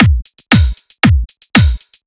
117 BPM Beat Loops Download